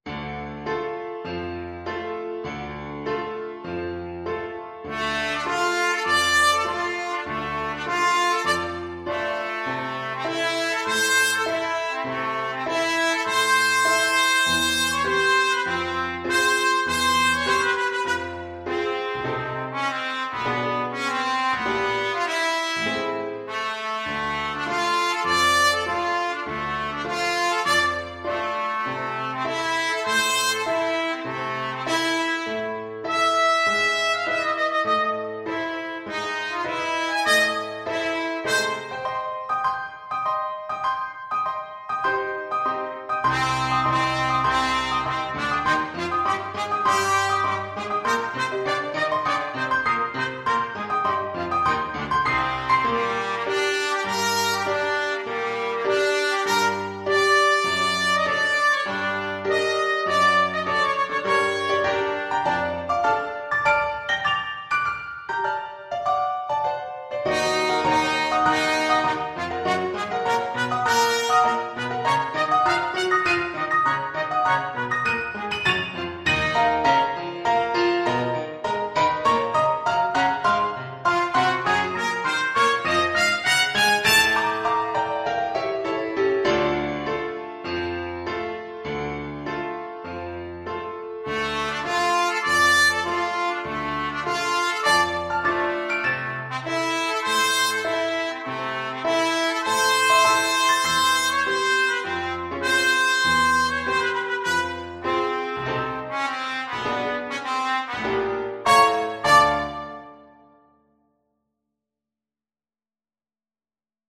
4/4 (View more 4/4 Music)
Classical (View more Classical Trumpet Music)